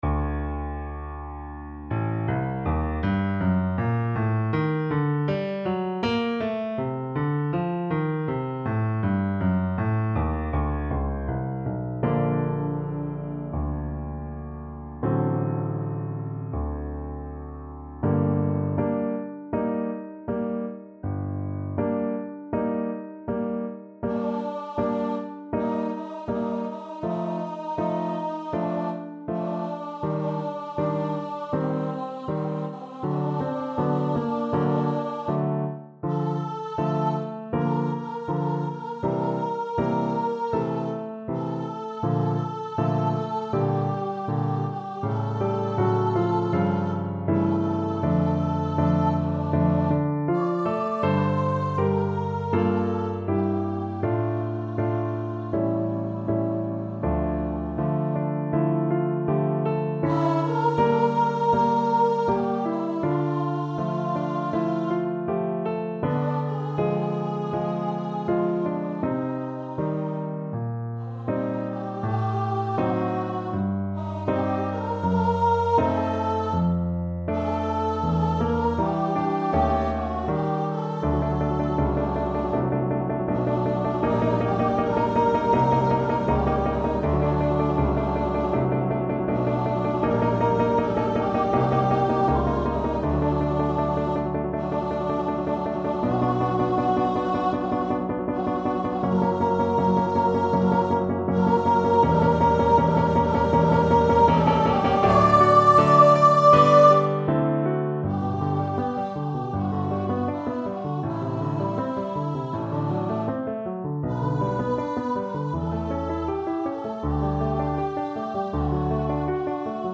Free Sheet music for Voice
G minor (Sounding Pitch) (View more G minor Music for Voice )
~ = 100 Molto moderato =80
Classical (View more Classical Voice Music)